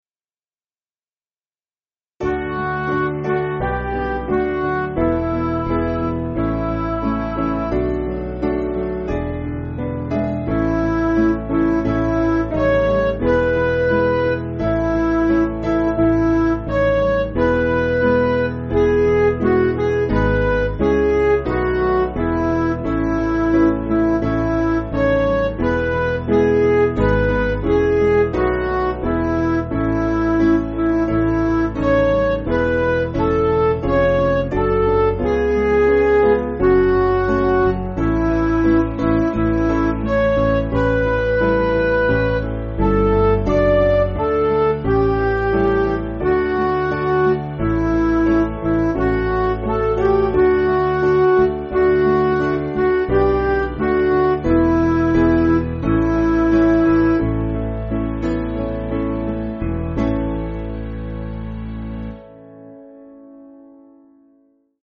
Vocals and Band   263.4kb Sung Lyrics 1.1mb